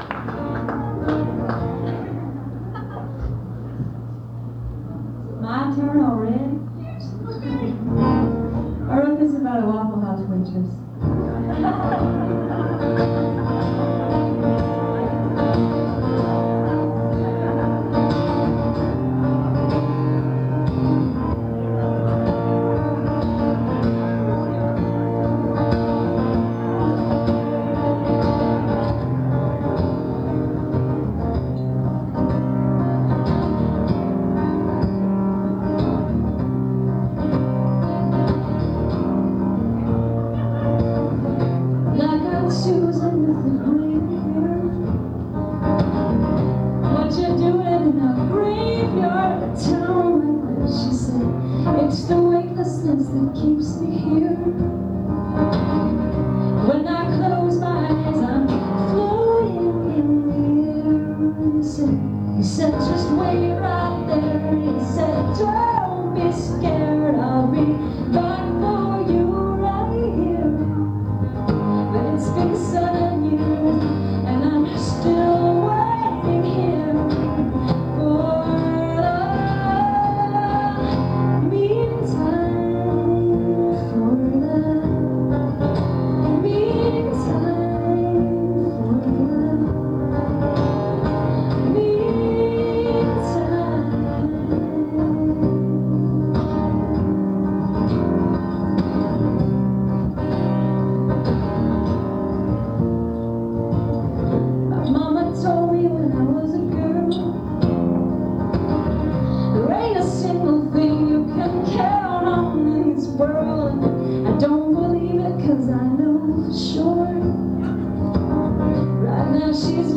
(songwriters in the round)
(first set)